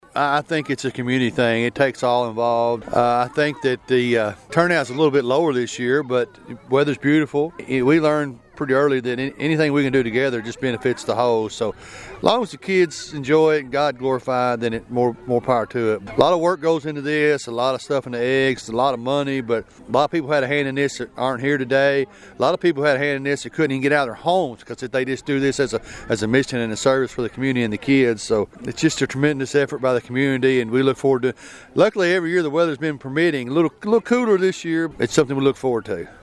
Children from around the area gathered at the Buddy Rogers Ballpark in Fredonia Saturday morning for the annual community Easter egg hunt.